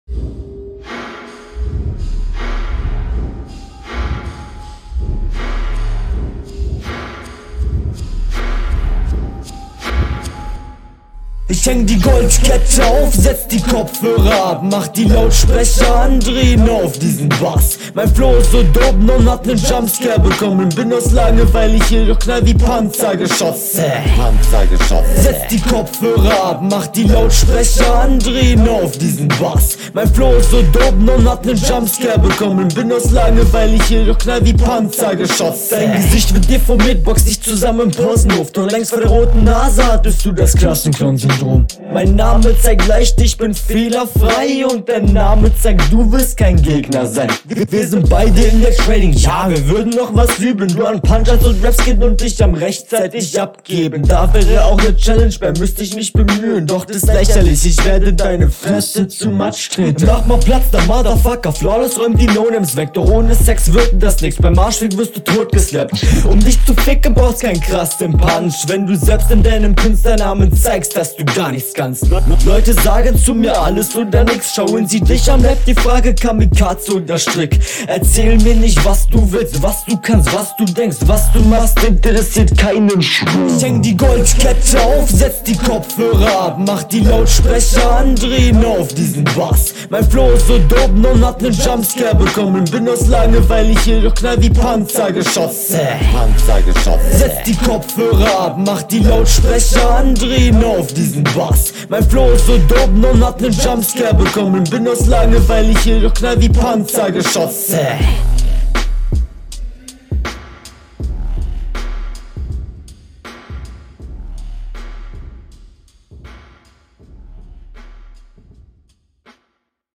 Die Soundqualität ist leider nicht gut.
Flow: Sein Stimmeinsatz ist gut, hätte bisschen Druckvoller sein können.
Erstmal fetter Beat.